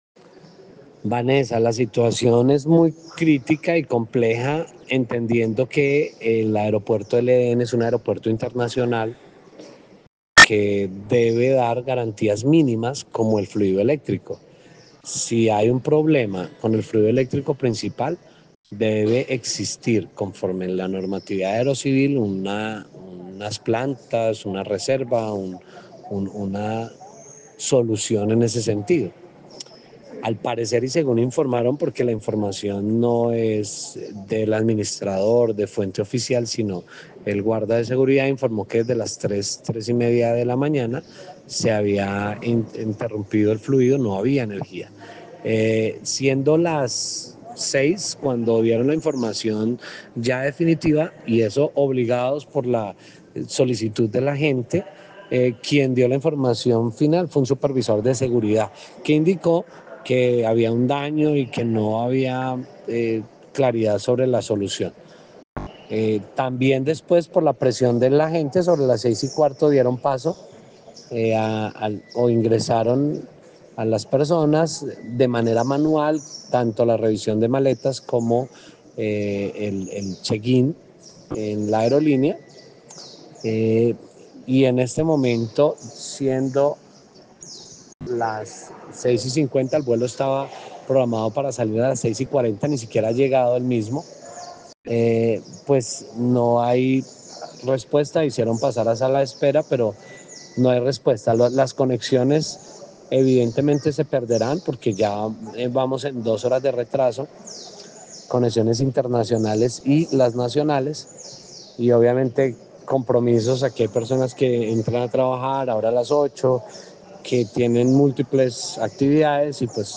Pasajero, aeropuerto El Edén de Armenia